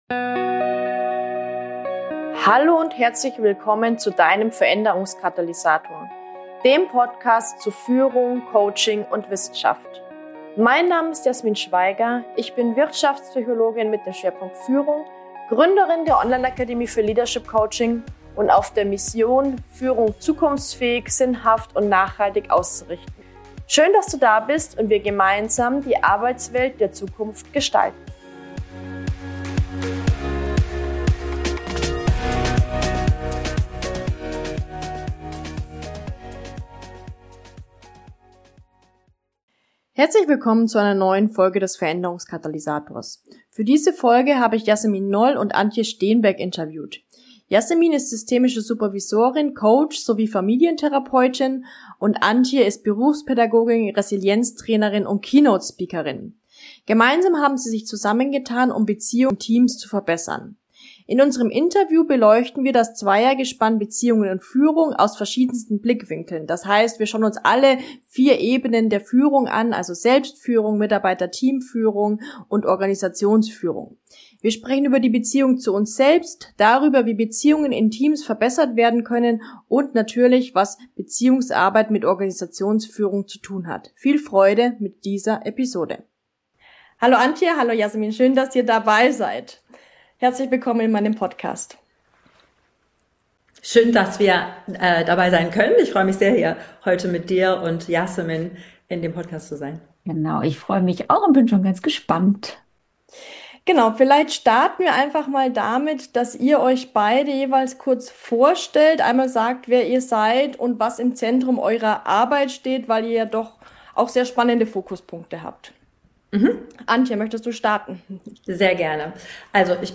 In unserem Interview beleuchten wir das Zweiergespann Beziehungen und Führung aus verschiedensten Blickwinkeln. Wir sprechen über die Beziehung zu uns selbst, darüber wie Beziehungen in Teams verbessert werden können und das Beziehungsarbeit mit Organisationsführung zu tun hat.